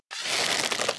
bow_draw.ogg